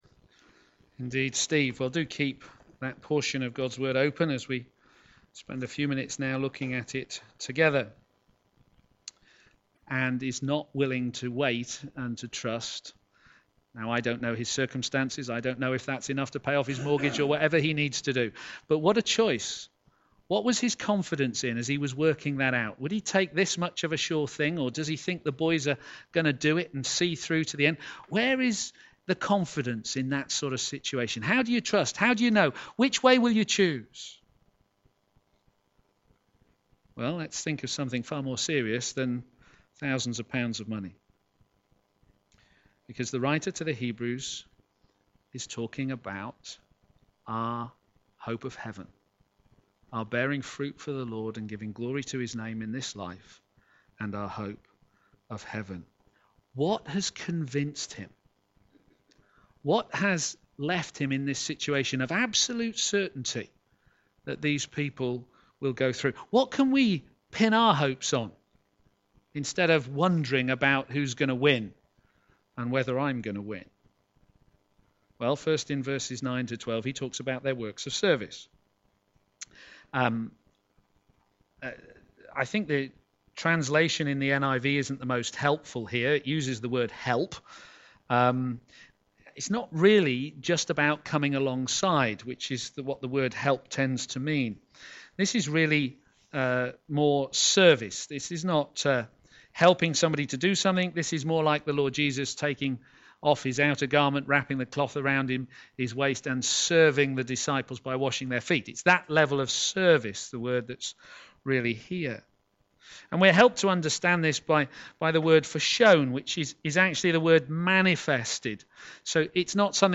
p.m. Service
Confident of Better Things Sermon